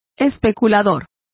Complete with pronunciation of the translation of stags.